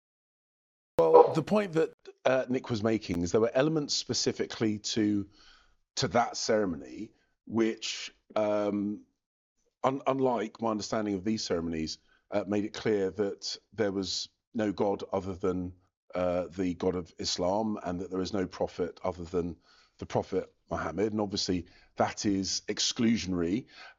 One prominent politician, a former leading government minister and former Foreign Secretary, said in a TV interview last Sunday, with reference to some of the words of the Adhan which was sounded at the ceremony, that this: